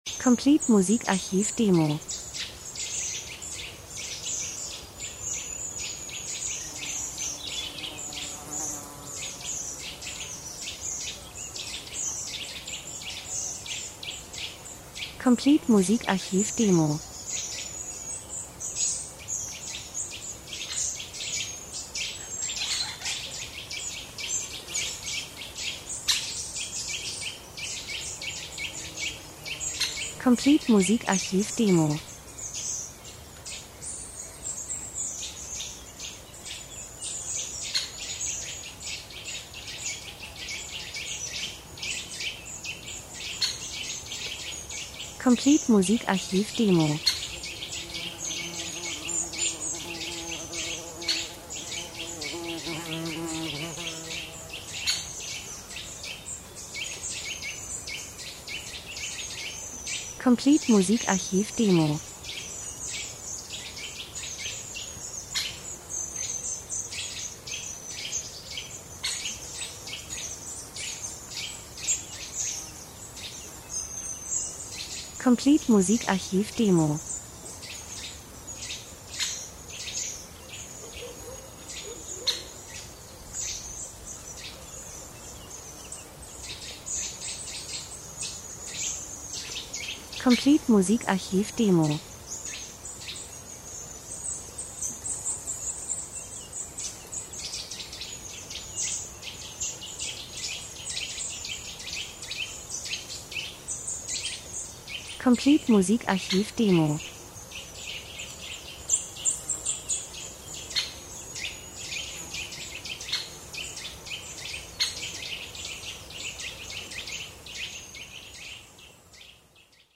Sommer -Geräusche Soundeffekt Natur Vögel Insekten 02:00